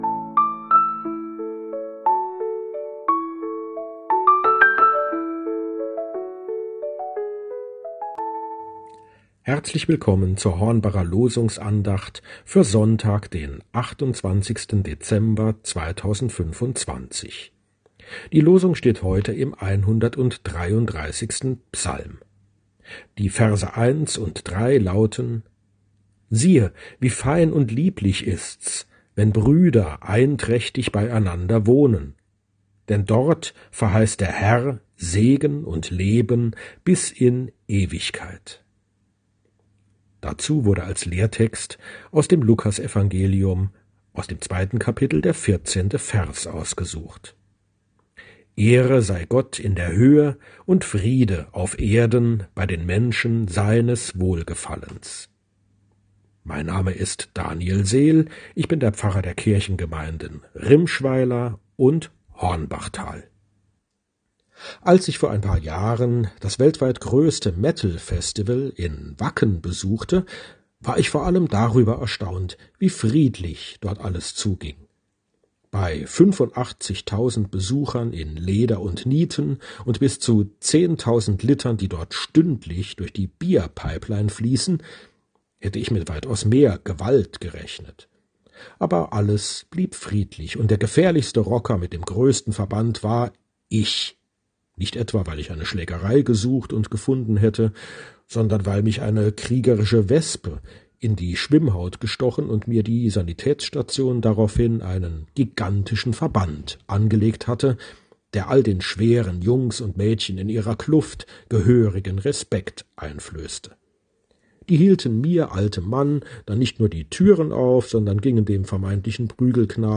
Losungsandacht für Samstag, 28.12.2025
Losungsandachten